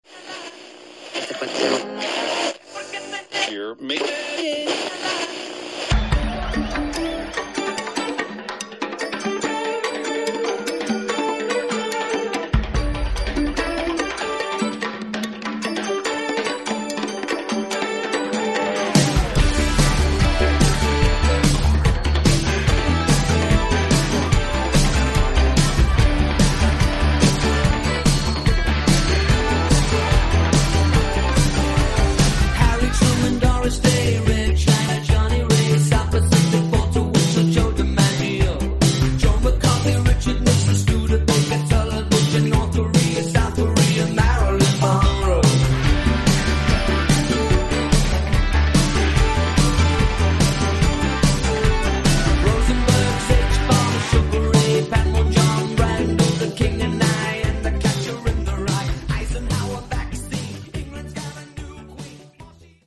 mixed & remixed by various DJs